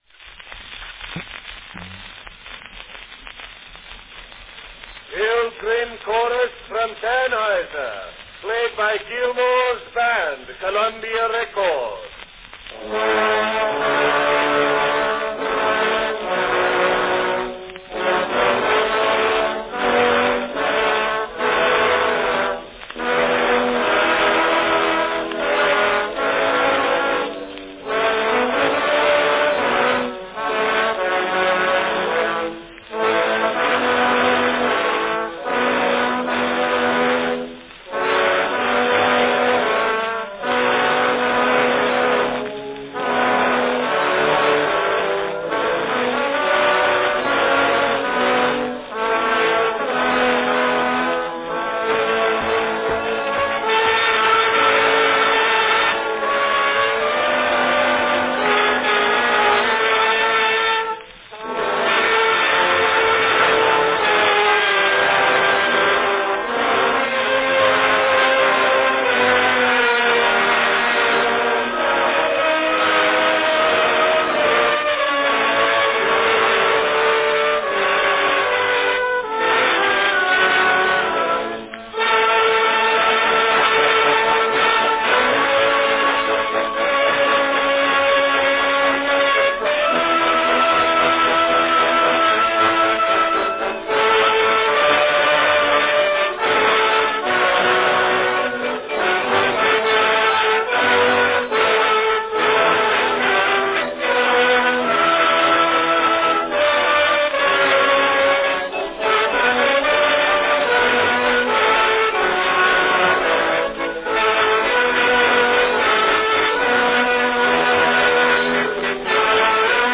A fine marching band recording from 1901 of the Pilgrim's Chorus from Tannhauser played by Gilmore's Band.
Cylinder # 1515 G (5-inch "grand" cylinder)
Category Band
Performed by Gilmore's Band
Enjoy this fine early 20th Century recording by Gilmore's Band recorded on a 5-inch diameter brown wax Columbia Phonograph Company "grand" cylinder.
The thunks and faint chatter prior to (and sometimes during!) the announcement are common features of many brown wax cylinder recordings.